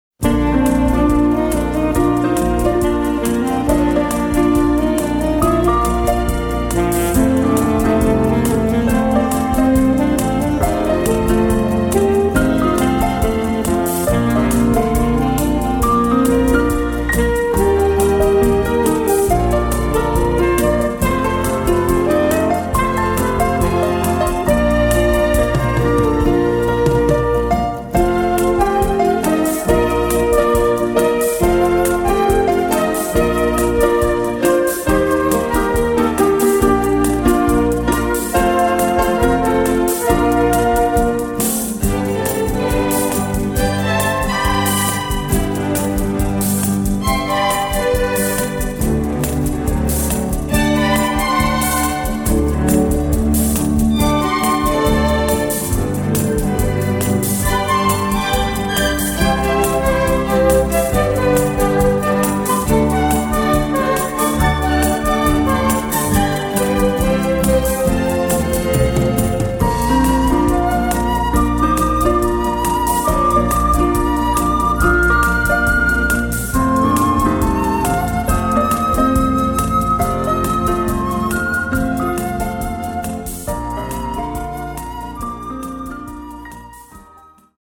Medium Beat